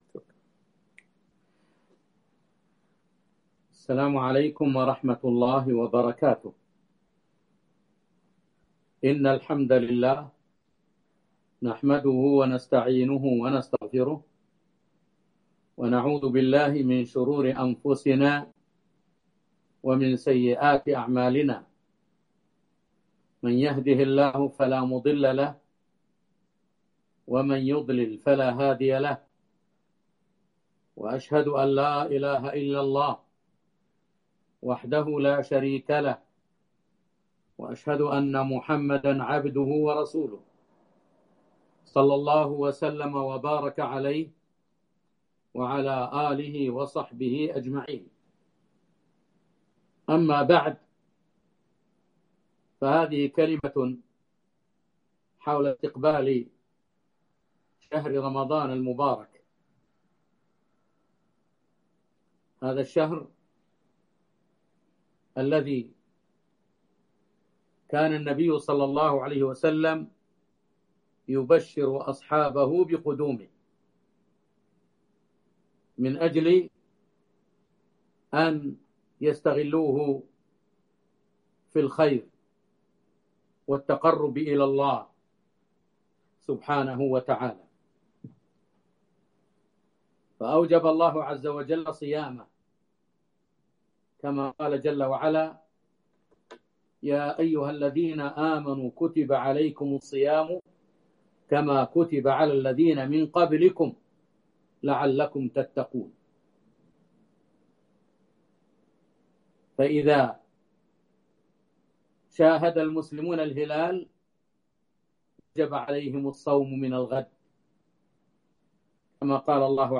كيف نستقبل رمضان ؟ كلمة عبر البث المباشر